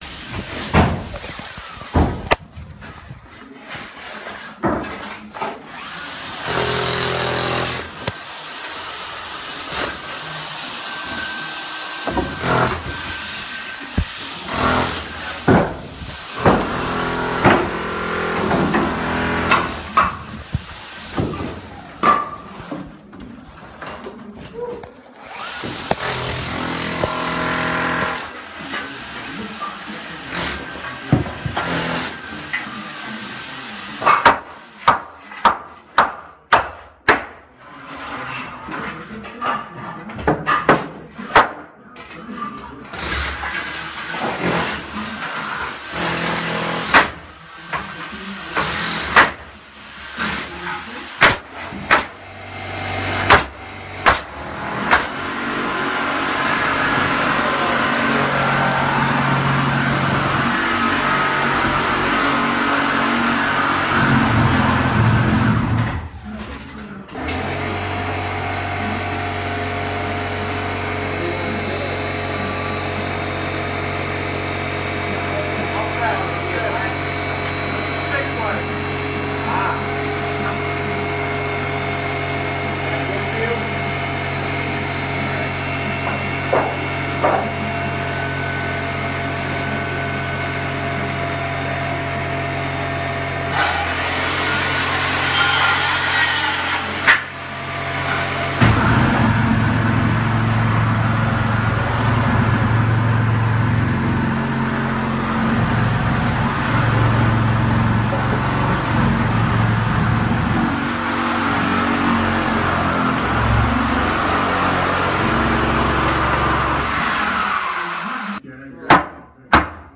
建筑声音 嘈杂的工作现场 免费的高质量声音效果
描述：施工声音嘈杂的工地免费高品质音效